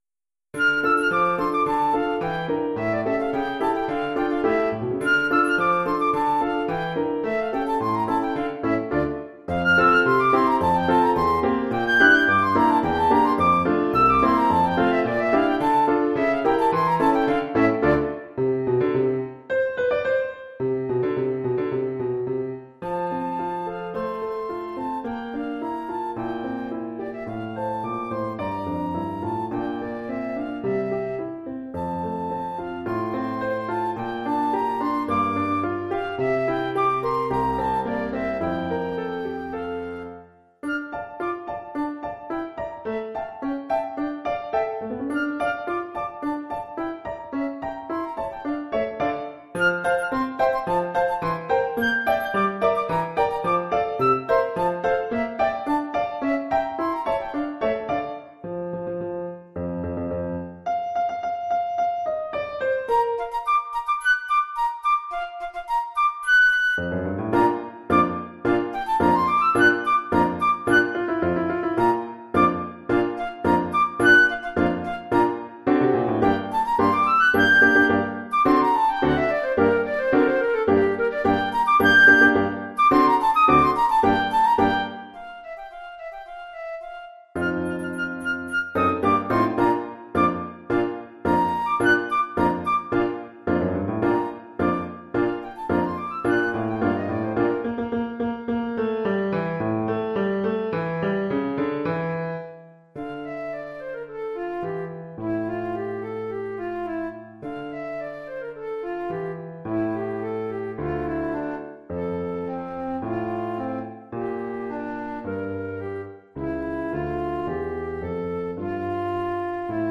Oeuvre pour flûte et piano.
pour flûte avec accompagnement de piano".